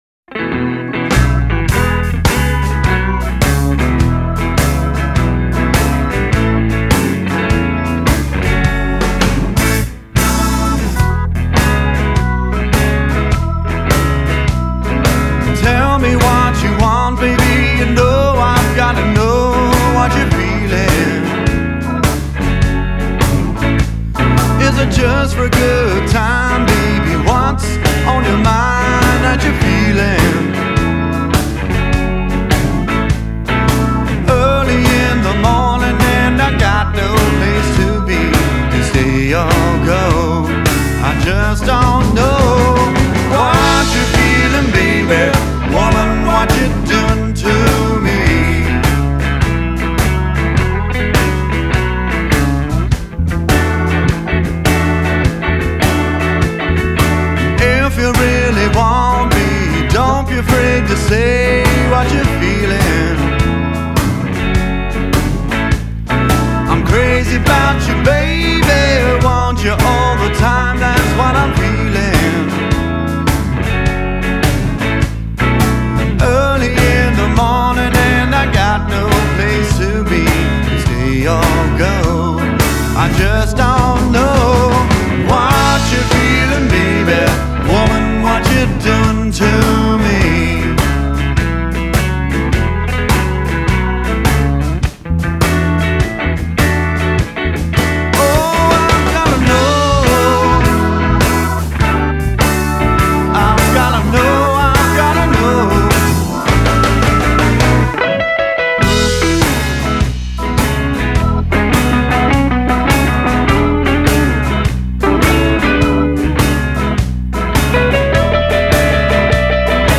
offers up a gutsy, yet classy sound